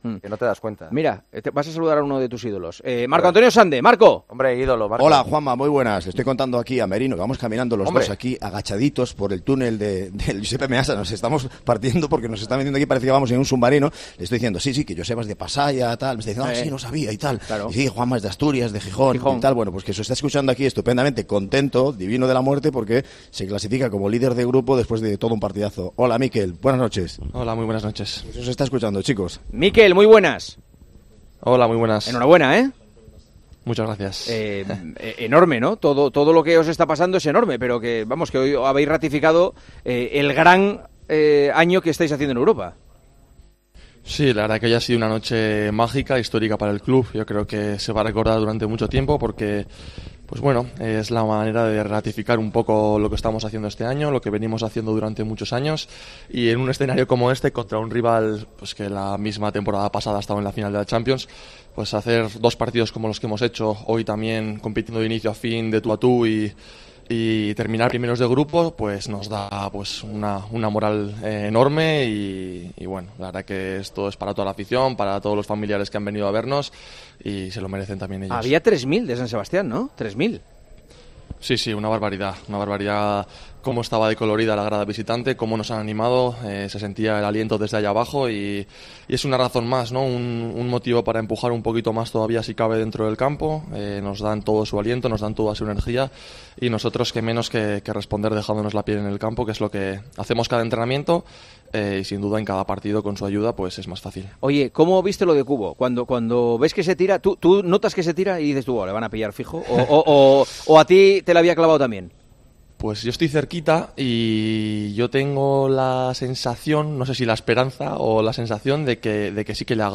El mediocentro de la Real Sociedad habló con Juanma Castaño y aseguró que el momento por el que pasa el club vasco es histórico y extraordinario.